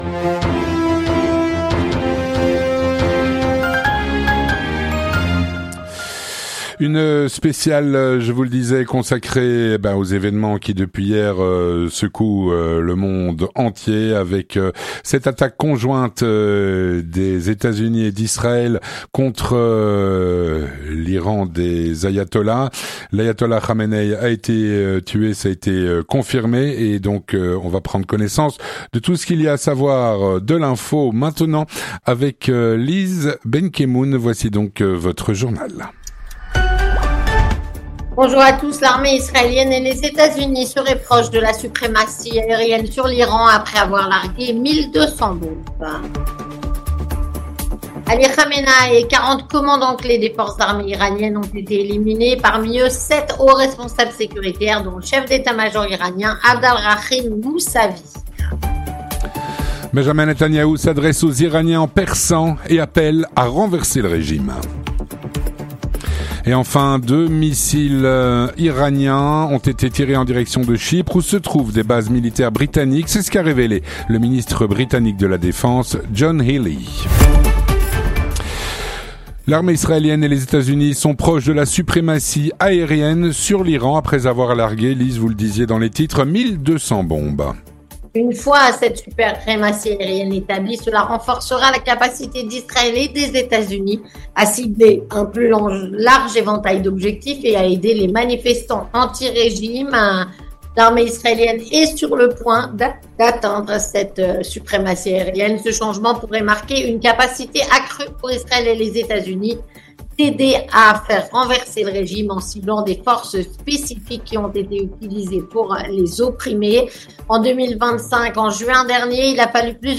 On fait le point en direct avec nos intervenants en Belgique et, bien sûr, en Israël.